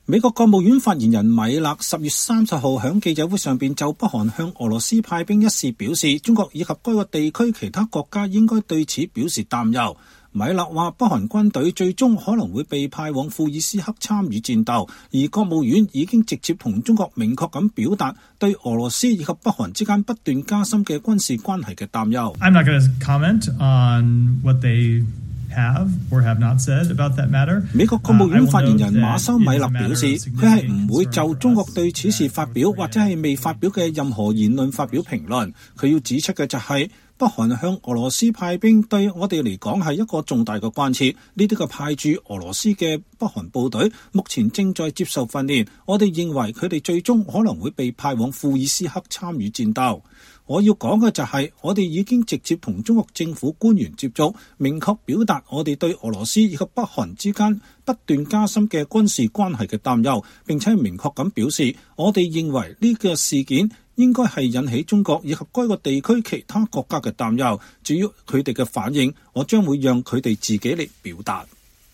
美國國務院發言人米勒10月30日在記者會上就北韓向俄羅斯派兵一事表示，“中國以及該地區其他國家應該對此表示擔憂。”
記者表示，“國務院如何評價中國對北韓向俄羅斯派兵一事保持沉默和不直接發表評論？”